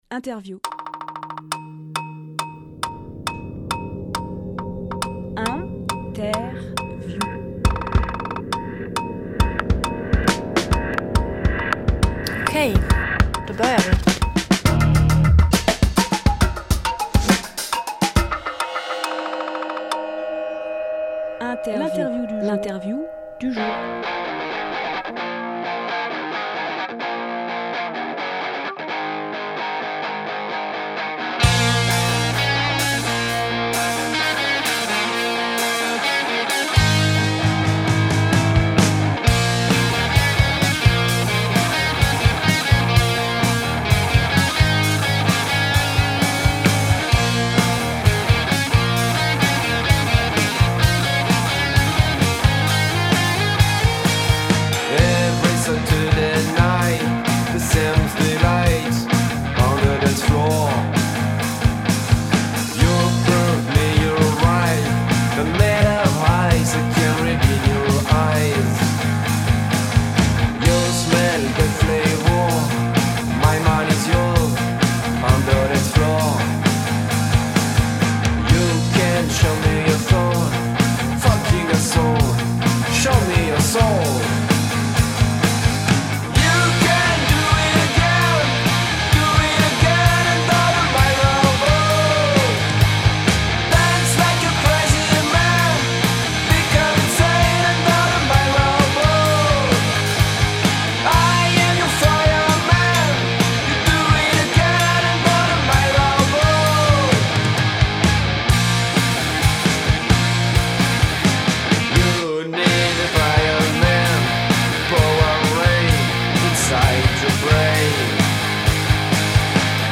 Emission - Interview YggDrasil Project Publié le 19 février 2025 Partager sur…
14.02.25 lieu : Studio Rdw durée